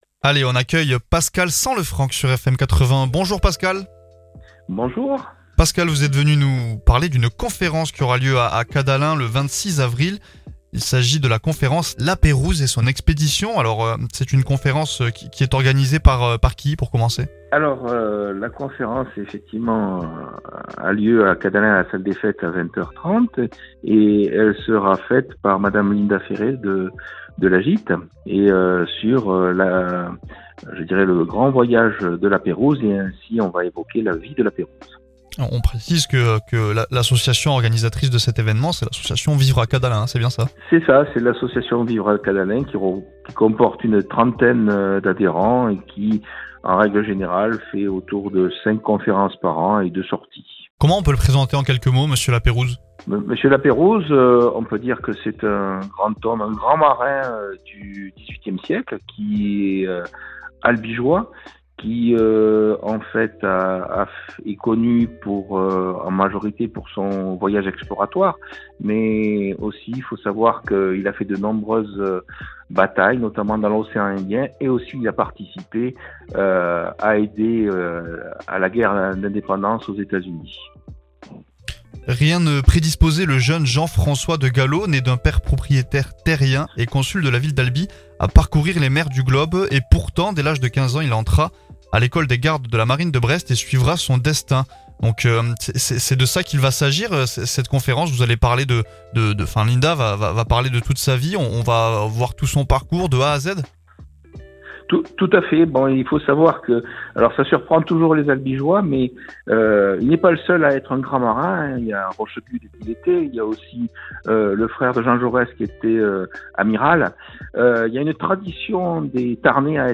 Conférence à Cadalens